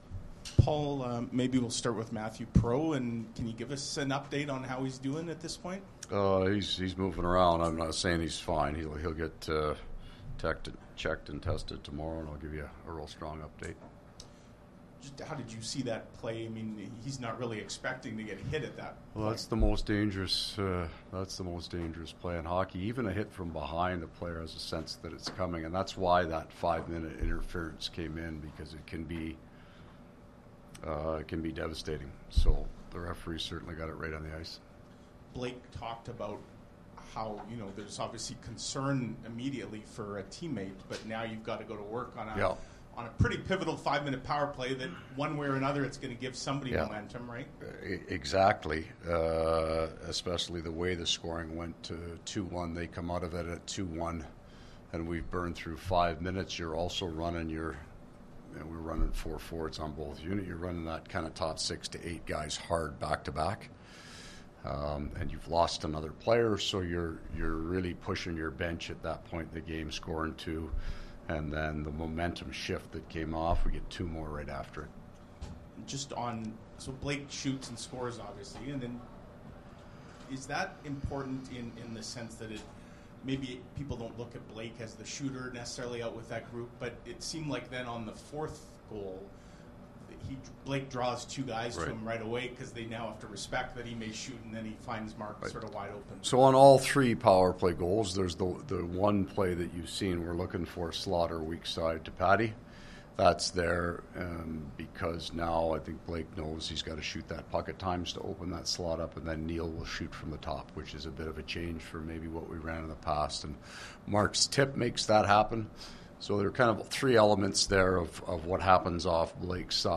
Post-game from the Jets dressing room as well as from Coach Maurice.